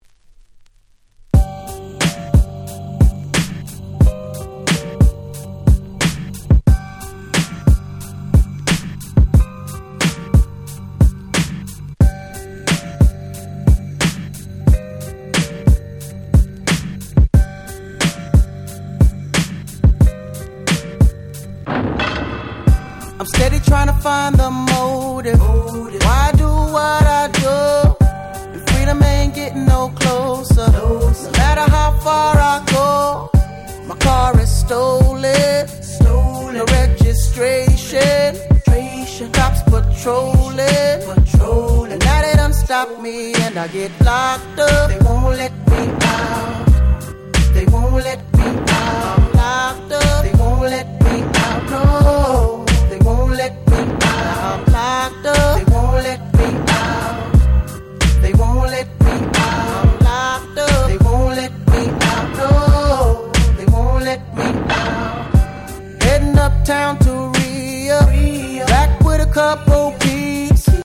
03' Super Hit R&B !!
それくらい普通にHip Hopな感じの楽曲です。